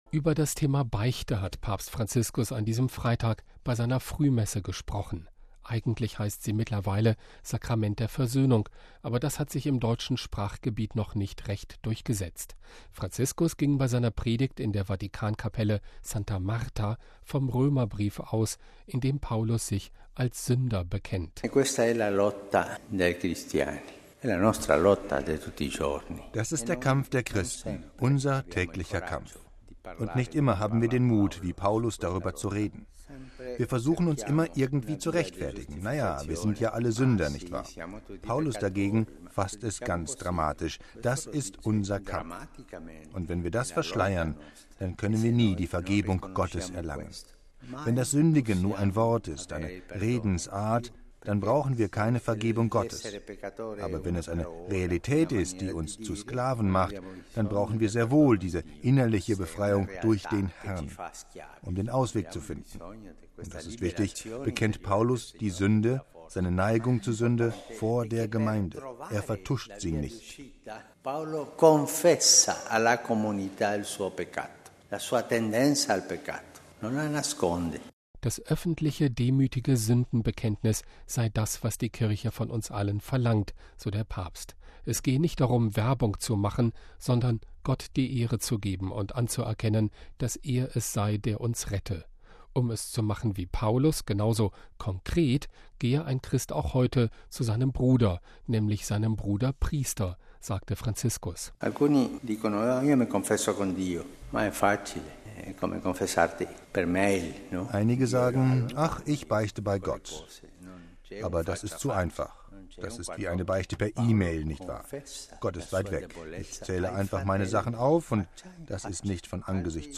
MP3 Über das Thema Beichte hat Papst Franziskus an diesem Freitag bei seiner Frühmesse gesprochen.
Franziskus ging bei seiner Predigt in der Vatikankapelle Santa Marta vom Römerbrief aus, in dem Paulus sich als Sünder bekennt.